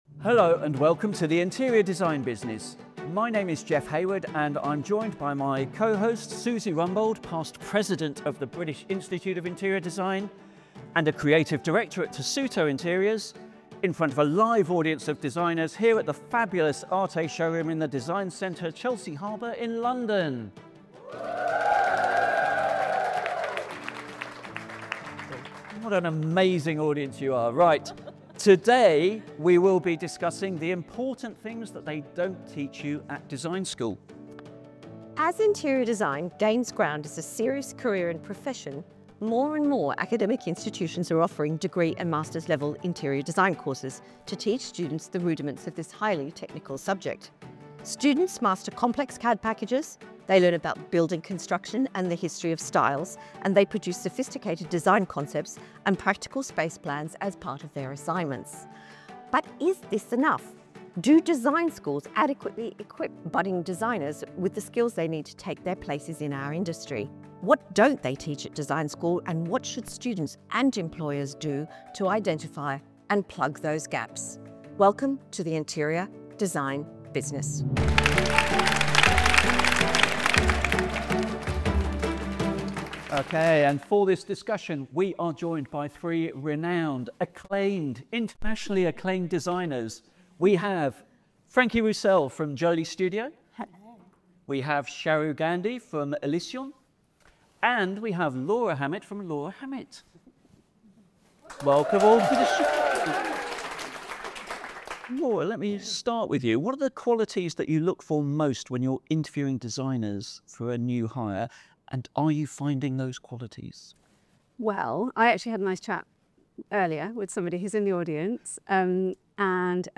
We explore the the important subjects that they don't teach at design school, and invite our panel to suggest what students and employers should do to identify and plug those gaps. This episode was recorded in front of a live audience of designers at